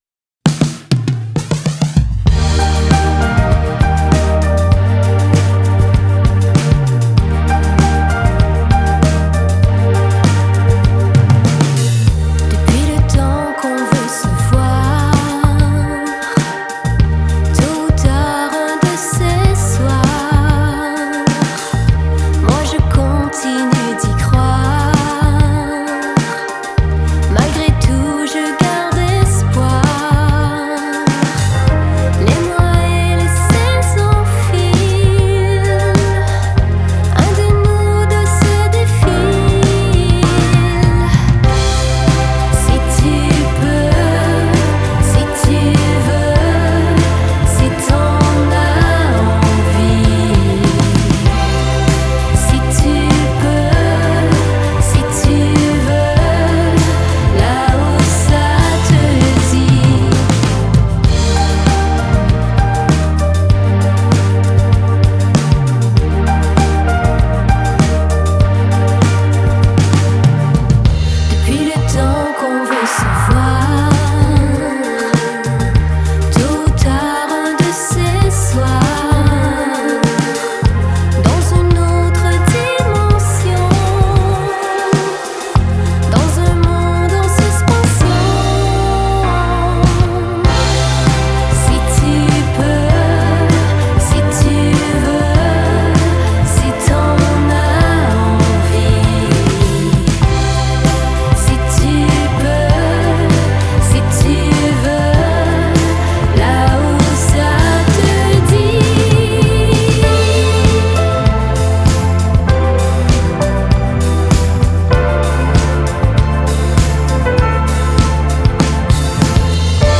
à la croisée du groove, du jazz et de la chanson